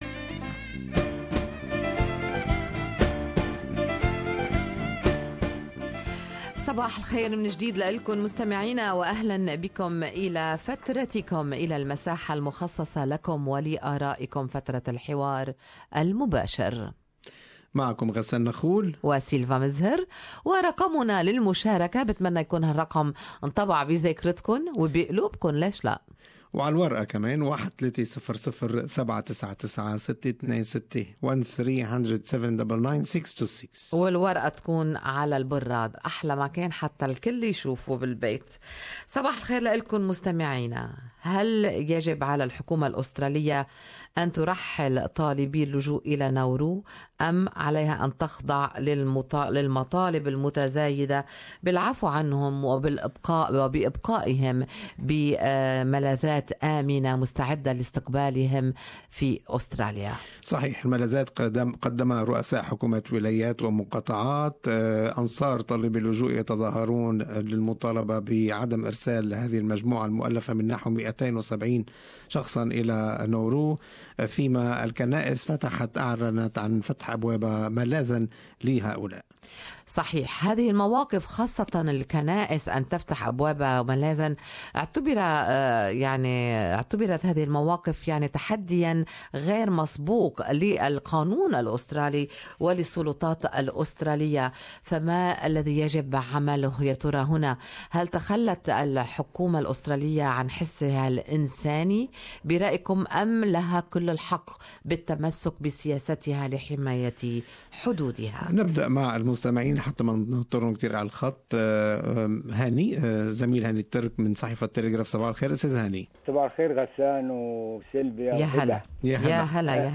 وماذا عن الأطفال منهم ؟؟اراء المستمعين في الحوار المباشر